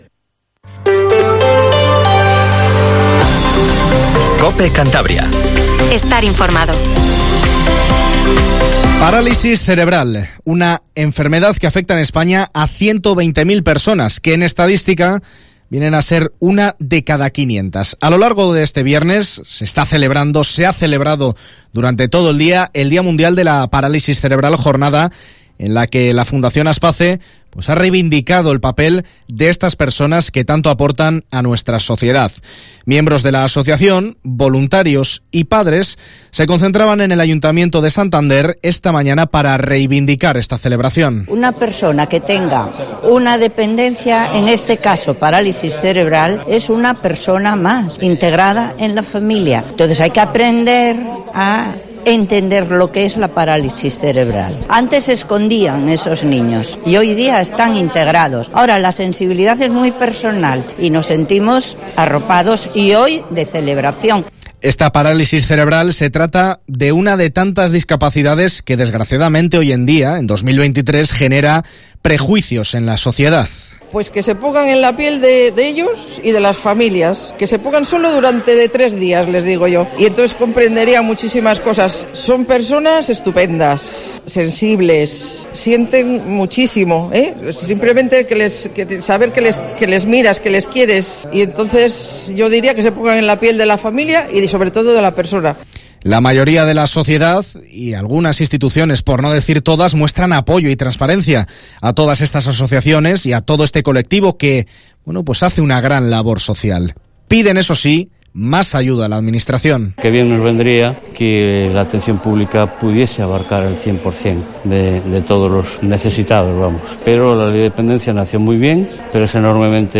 Informativo LA LINTERNA en COPE CANTABRIA 19:50